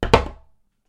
战斗的声音 " 木头冲击1
描述：由衣架，家用餐具和其他奇怪物品制成的剑声。
Tag: 战斗 战争 弗利 战斗 行动